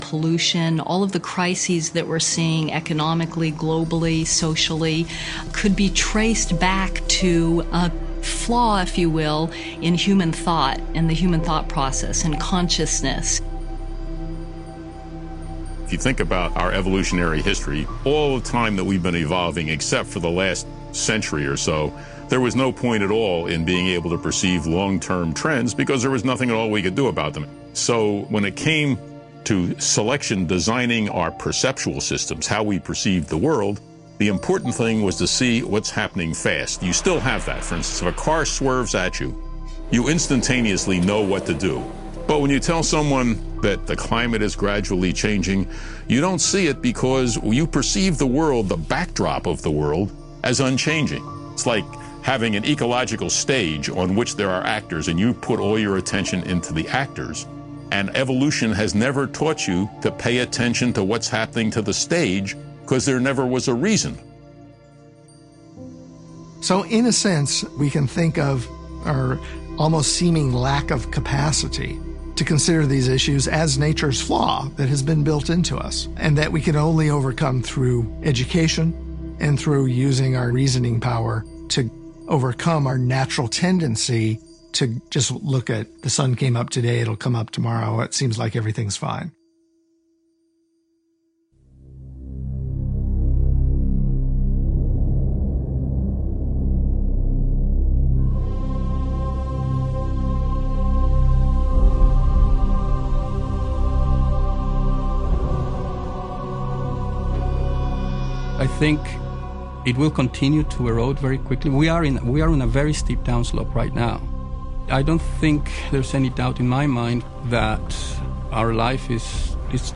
A wide range of voices mainly from the academic community contribute our first piece this week which is a radio adaptation of the video, Call of Life.
We then take a 4 minute snippet of a video from PETA, entitled 'Meet Your Meat' about the suffering inflicted on animals by the agribusiness industry.